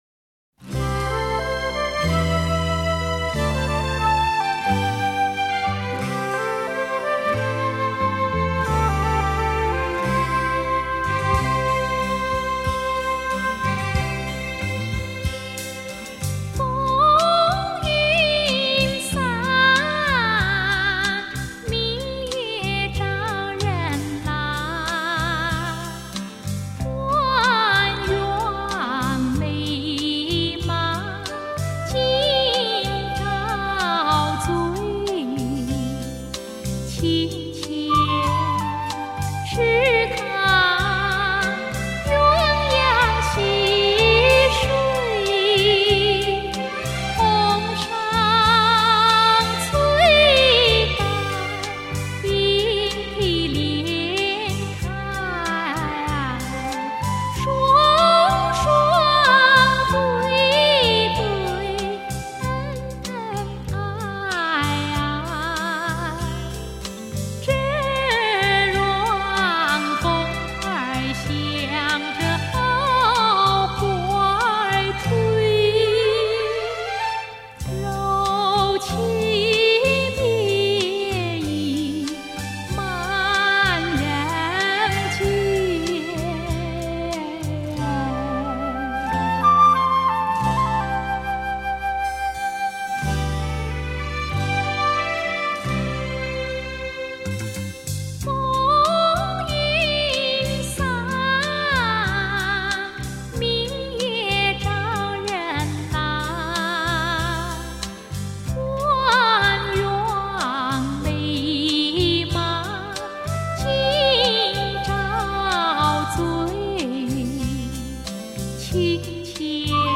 以迷人的声音演绎多首盛名金曲